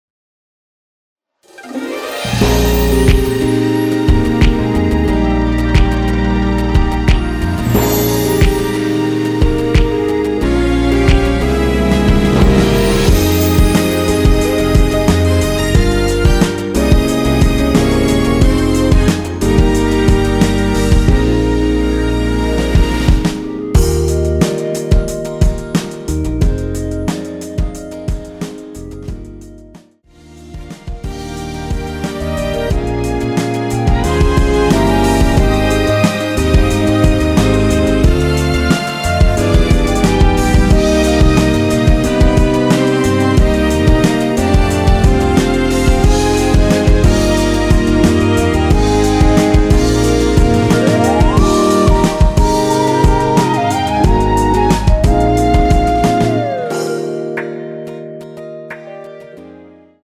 원키 멜로디 포함된 MR입니다.
Eb
앨범 | O.S.T
노래방에서 노래를 부르실때 노래 부분에 가이드 멜로디가 따라 나와서
앞부분30초, 뒷부분30초씩 편집해서 올려 드리고 있습니다.
중간에 음이 끈어지고 다시 나오는 이유는